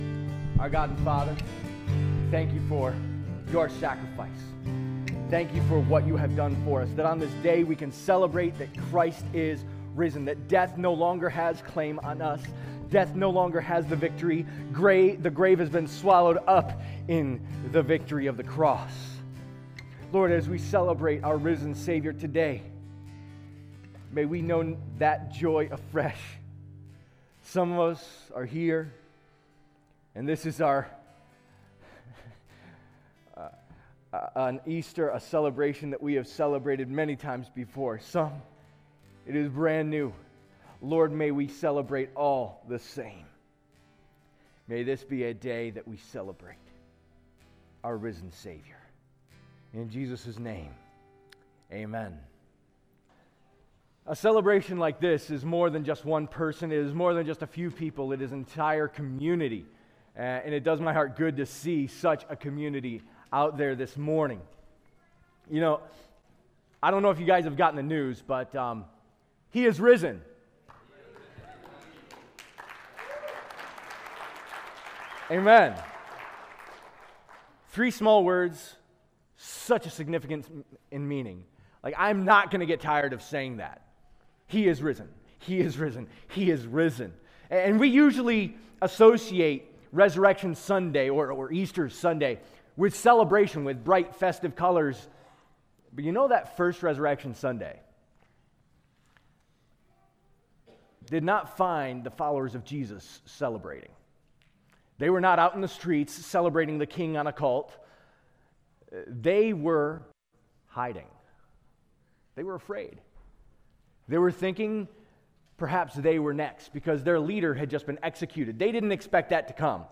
← Newer Sermon Older Sermon →